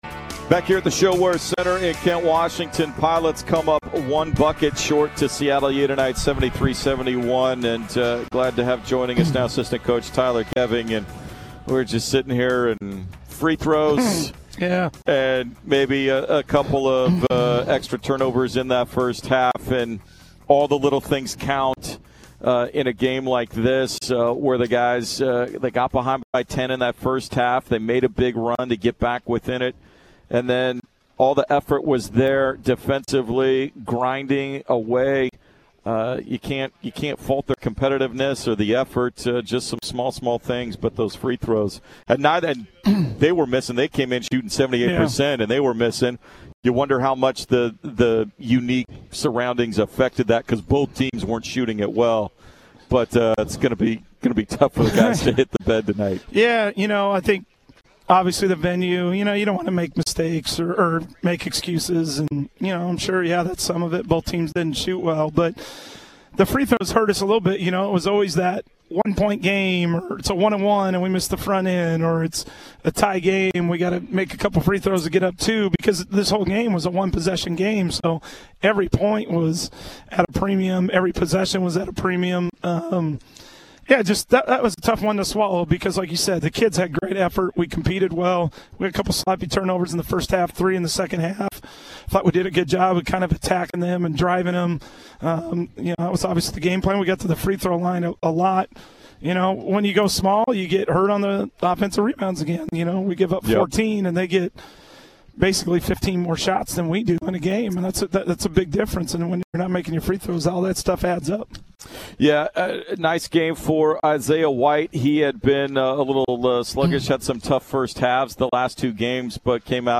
Men's Hoops Post-Game Interview at Seattle U